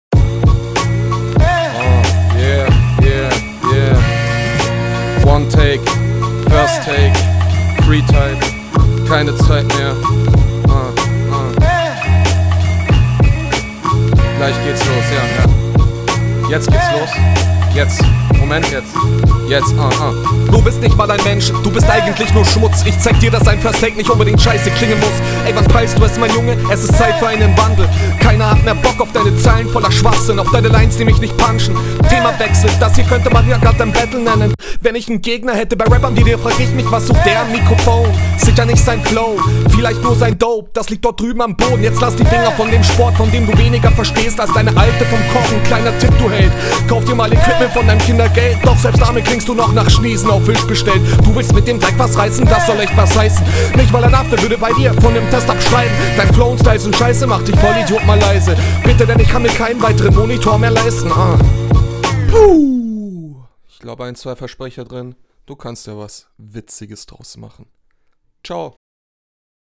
Ok Oldschool Beat, steht dir wohl besser wa? Mische ist diesmal bisschen dumpf.
Doper Beat.
Sehr sauber geflowt, nice.